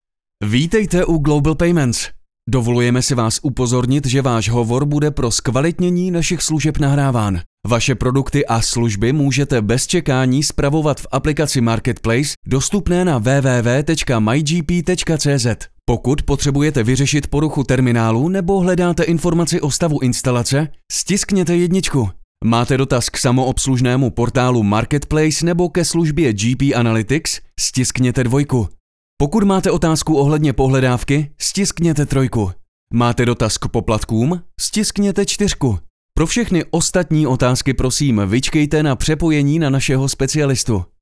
Obdržíte kvalitní zvukovou stopu - voiceover (wav/mp3), vyčištěnou od nádechů a rušivých zvuků, nachystanou pro synchronizaci s vaším videem.
Mužský voiceover do Vašeho videa (Voiceover / 90 sekund)